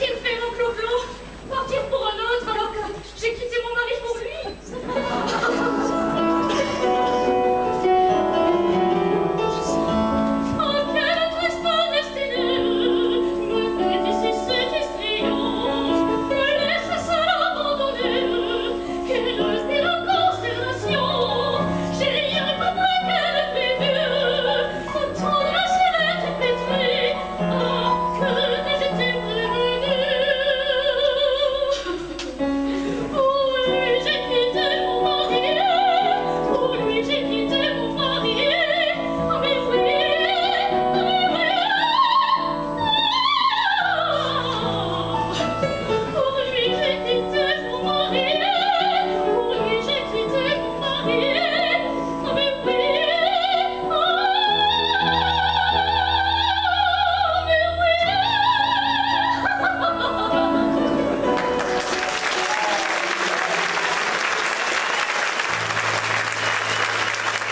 Chansons maçonneuses                                           Suivante
Chanté à la sortie de la tenue du sketch “la Drague” (version masculine)
Enregistrement public, Festival 2017